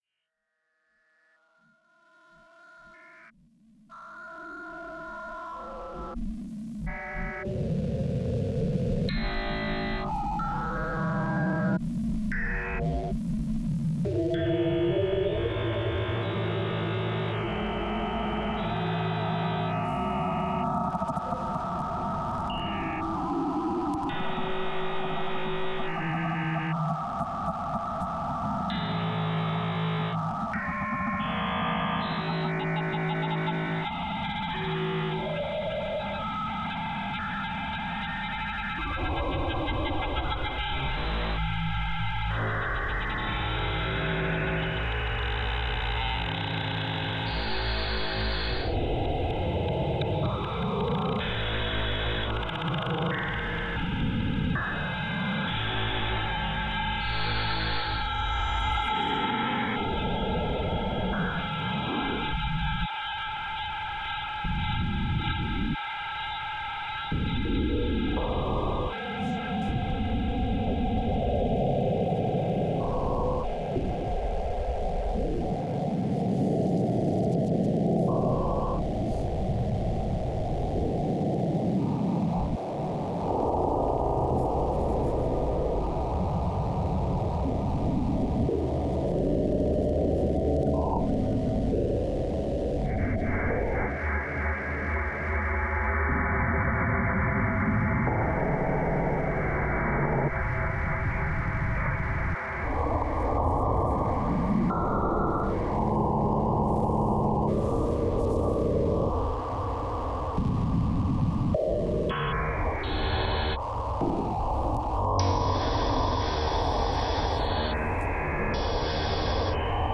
a barrier’s hum, sporadic clangor within the river’s drift, aggregates form,
The Barrier’s mechanized hum and occasional clangs blend with the soft lapping of the tide against its base, creating a metallic symphony that resonates with the early morning light.
The factory’s deep, mechanical groans and the steady churn of machinery create a relentless beat, a reminder of the ceaseless transformation that underpins the city’s growth.
The soundscape shifts with the proximity of Canary Wharf, where the river’s edge bristles with the high-rise towers of commerce.
The low hum of business, punctuated by the occasional drone of a helicopter or the distant wail of a siren, blends with the natural sounds of the river.